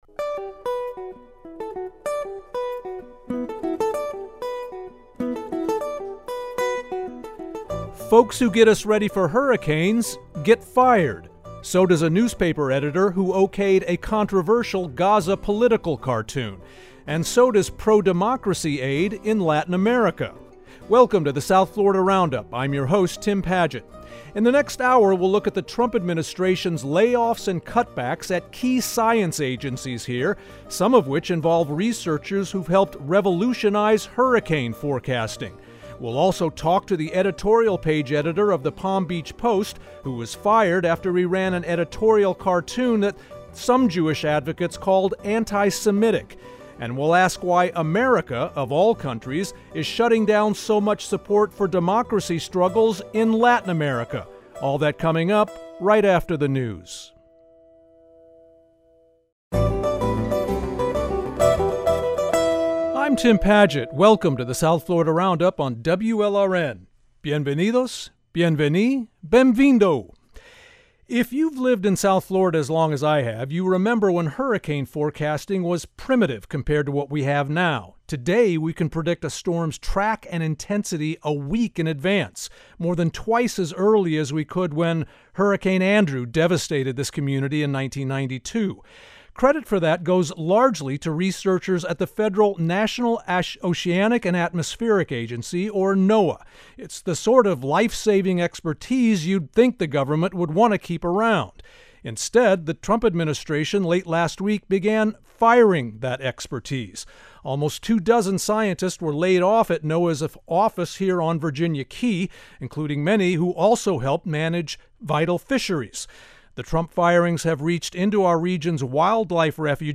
Each week, journalists and newsmakers from South Florida analyze and debate some of the most topical issues from across the region.